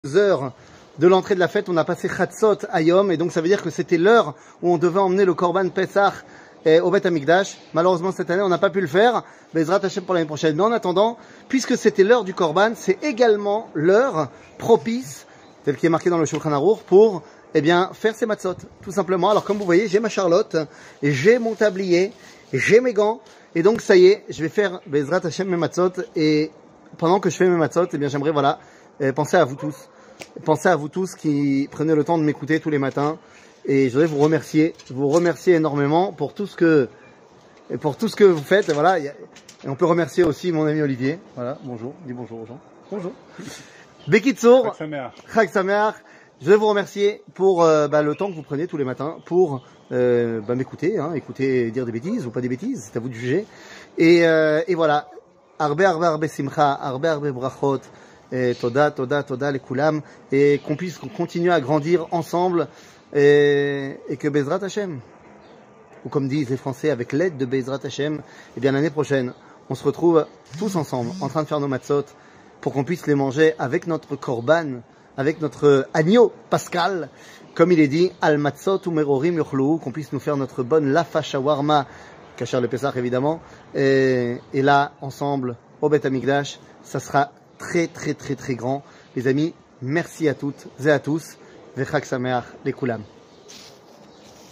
שיעור מ 05 אפריל 2023 01MIN הורדה בקובץ אודיו MP3